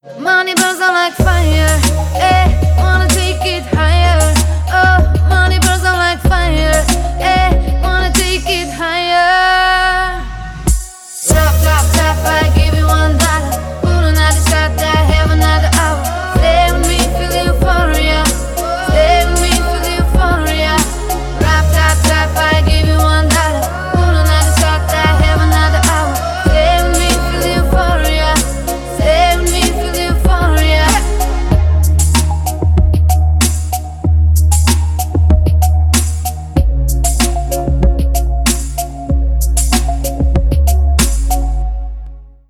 • Качество: 320, Stereo
поп
ритмичные
чувственные
RnB
красивый женский голос